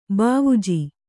♪ bāvuji